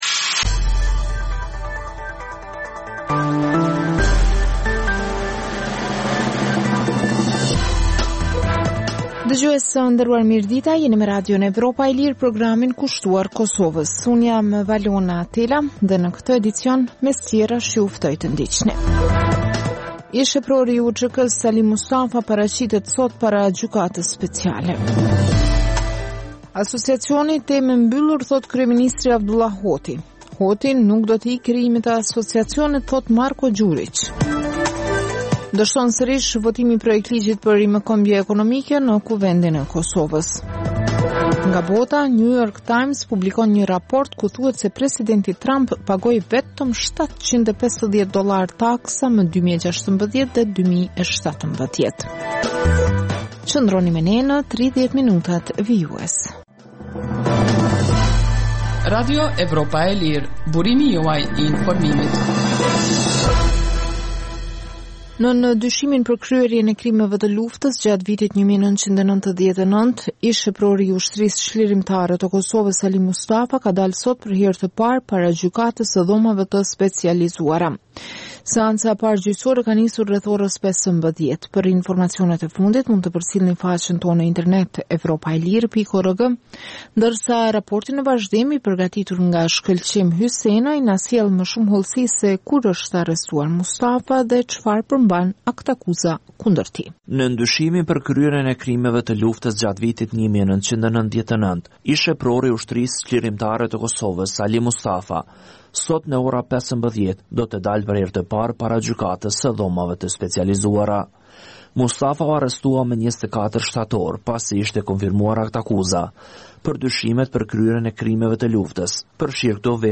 Emisioni i orës 16:00 është rrumbullaksim i zhvillimeve ditore në Kosovë, rajon dhe botë. Rëndom fillon me kronikat nga Kosova dhe rajoni, dhe vazhdon me lajmet nga bota. Kohë pas kohe, në këtë edicion sjellim intervista me analistë vendorë dhe ndërkombëtarë për zhvillimet në Kosovë.